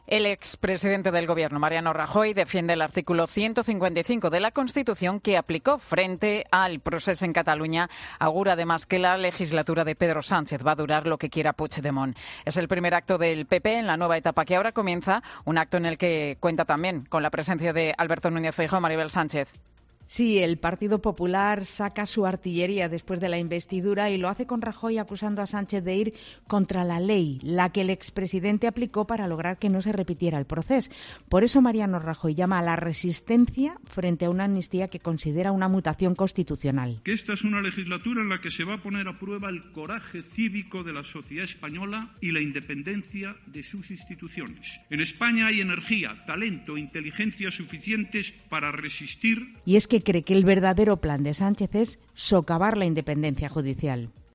En la presentación del desayuno del presidente de la Xunta, Alfonso Rueda, organizado por el foro Nueva Economía, Rajoy ha recalcado que hay que "seguir defendiendo la necesaria igualdad de los españoles" ante "tanto ruido y tanta falsedad" de Sánchez.